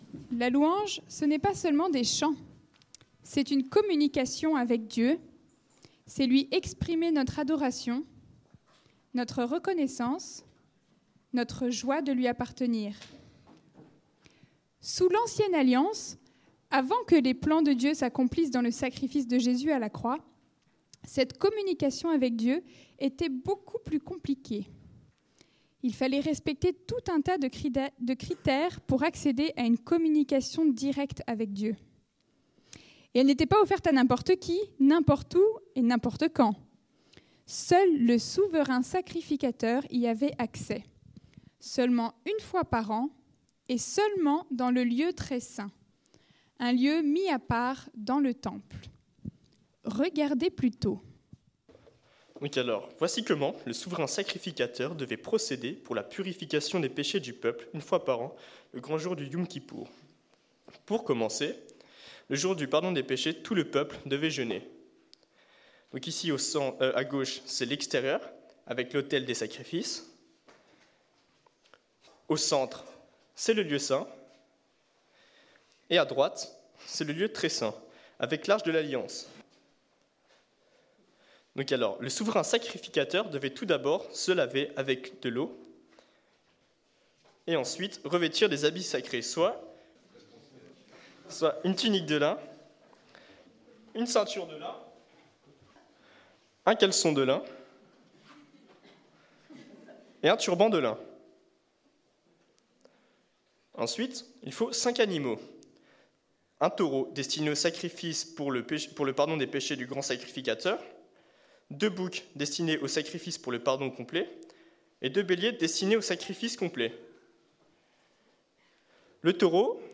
Preacher: Groupe de Jeunes | Series:
Culte du GDJ du 27 avril